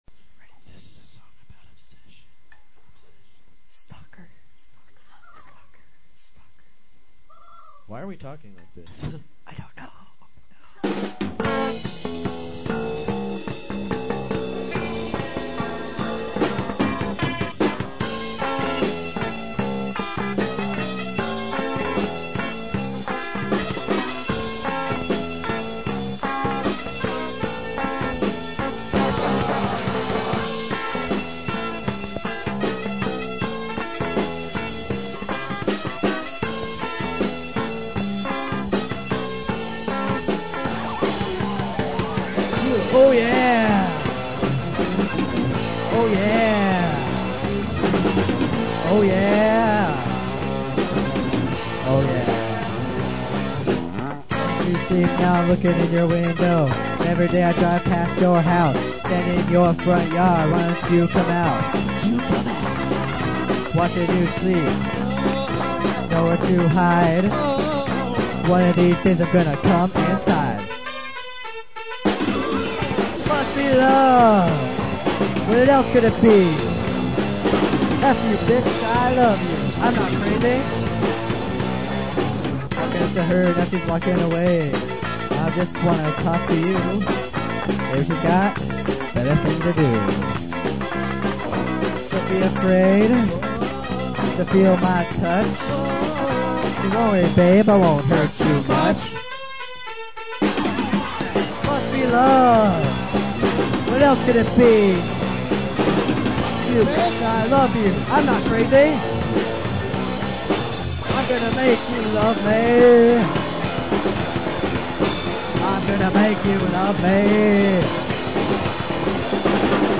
Guitar
Drums/Back-upVocals
Bass/Vocals
Keyboard